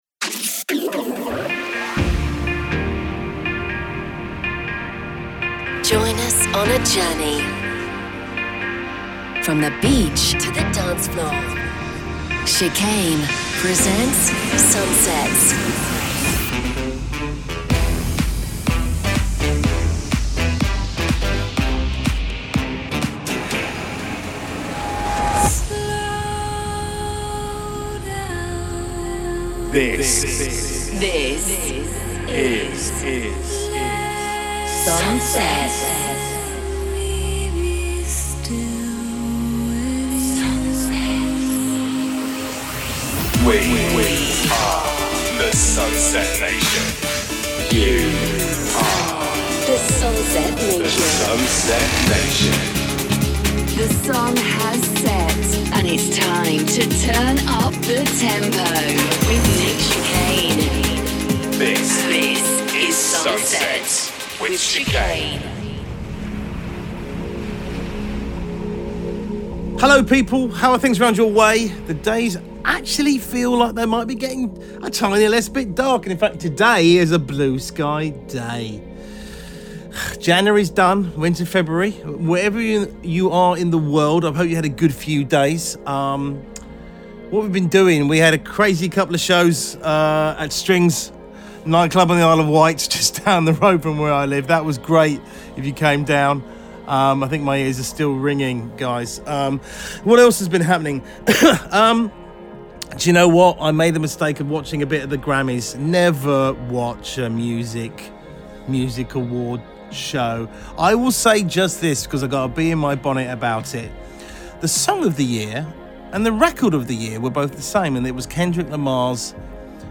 From the beach to the dancefloor...